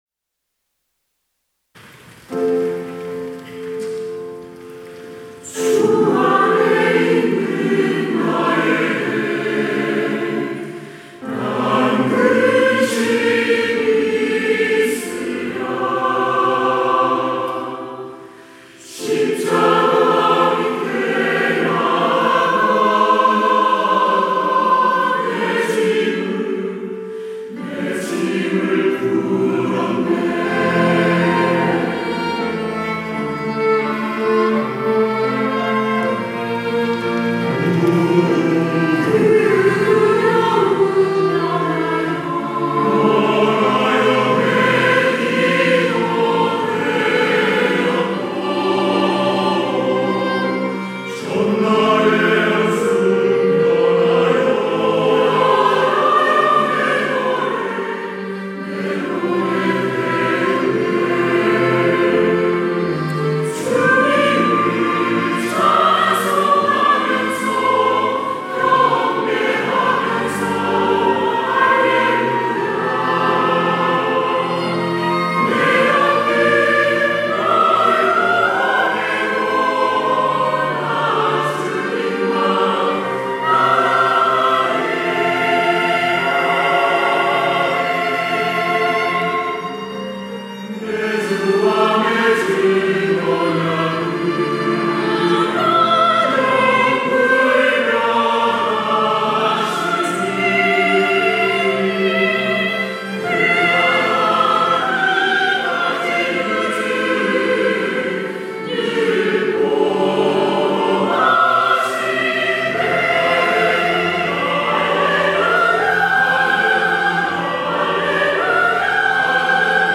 할렐루야(주일2부) - 주 안에 있는 나에게
찬양대